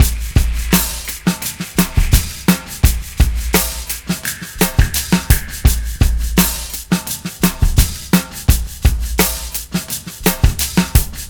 Chiswick Shaker 01.WAV